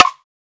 Snares
SNARE.7.NEPT.wav